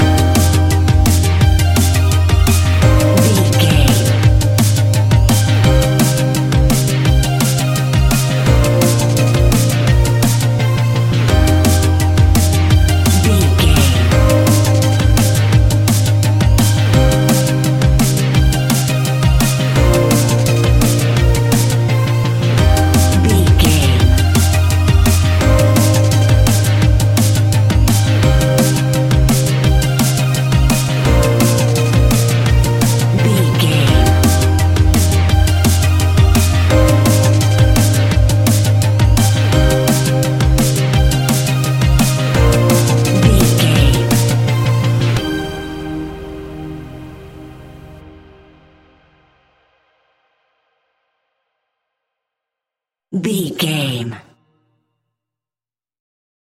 Ionian/Major
C♭
techno
trance
synths
synthwave
instrumentals